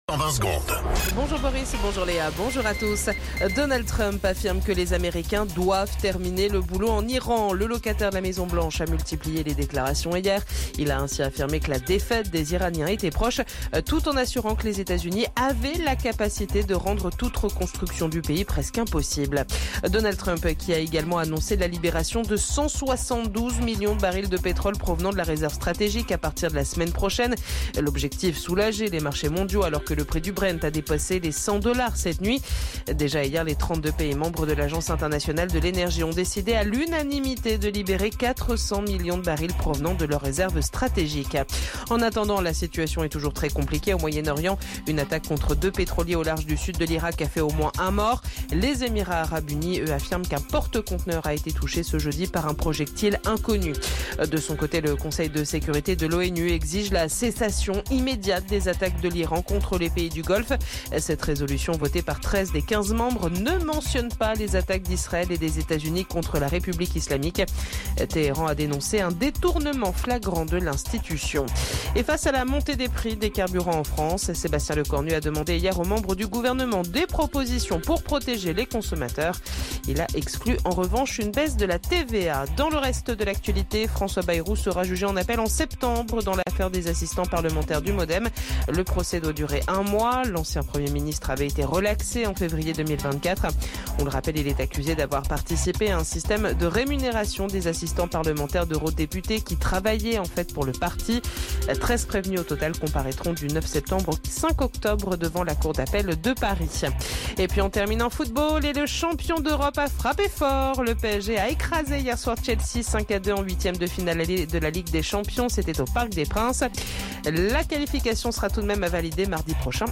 Flash Info National 12 Mars 2026 Du 12/03/2026 à 07h10 .